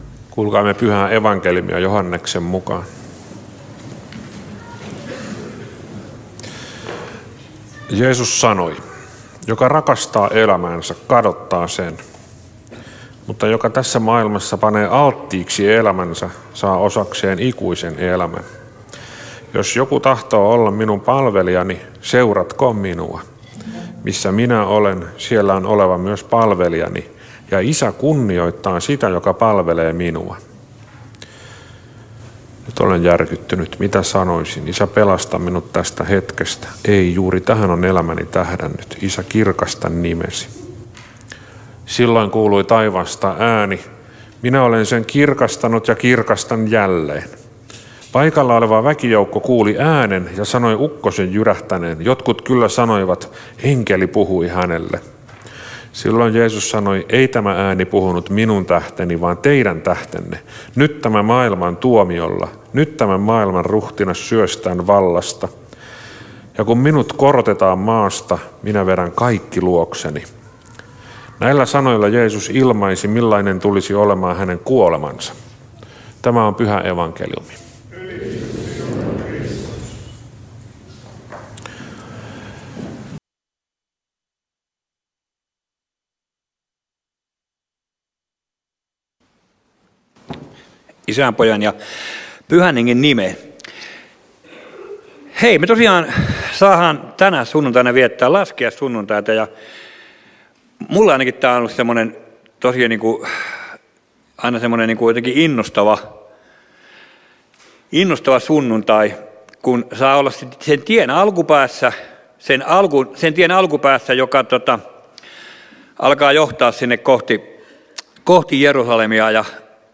Turku